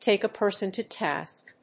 アクセントtàke a person to tásk